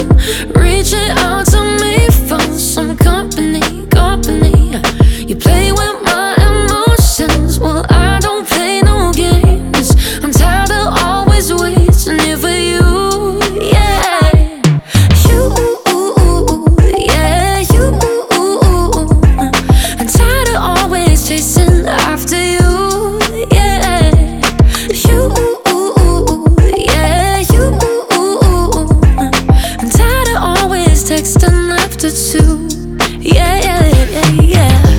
Жанр: Поп / R&b / K-pop / Соул